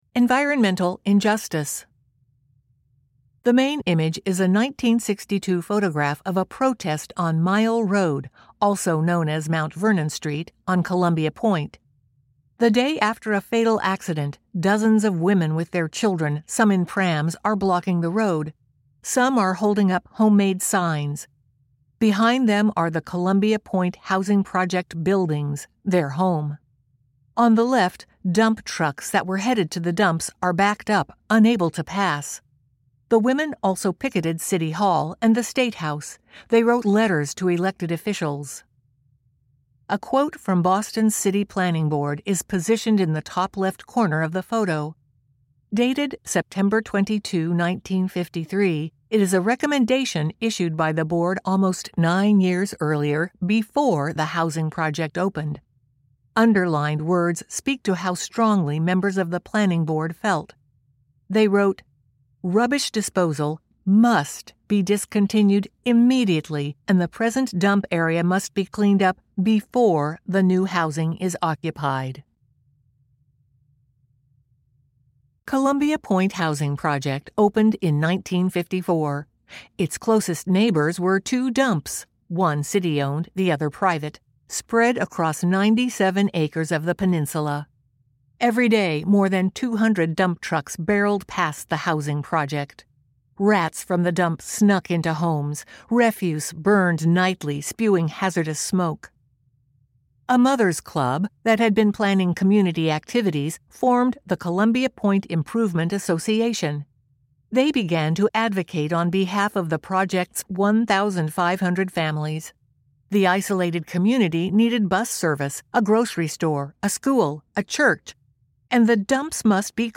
Our gratitude to the Perkins School for the Blind Recording Studio